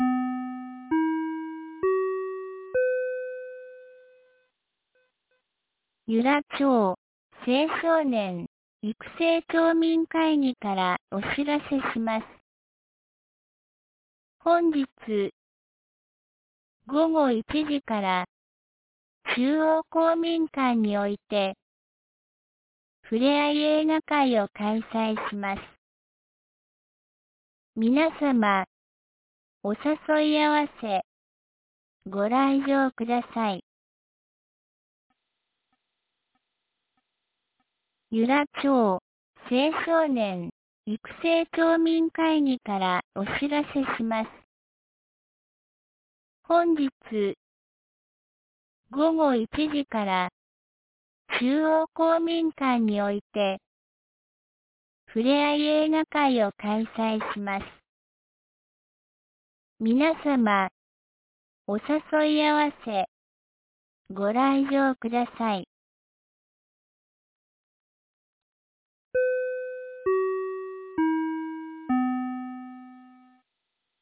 2025年08月09日 07時51分に、由良町から全地区へ放送がありました。